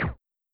Silencer Shoot.wav